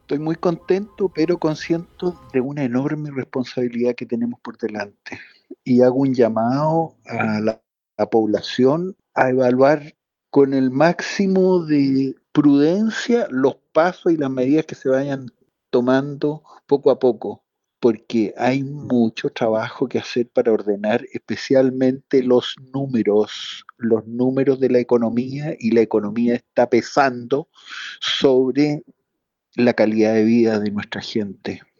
Cifras que no dejaron indiferente a los senadores de la zona, que en conversación con La Radio, dieron cuenta de sus proyecciones y análisis, a solo minutos de conocerse que el republicano será el próximo presidente.